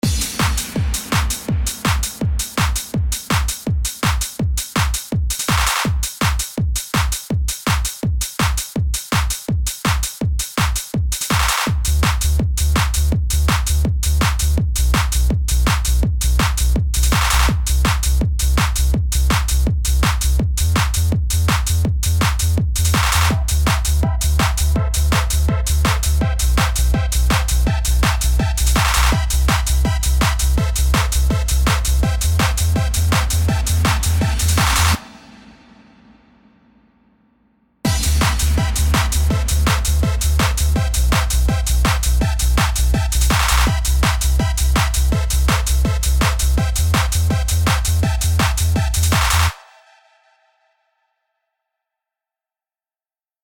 Eurobeattest.mp3 📥 (1.02 MB)
kinda sounds basic doe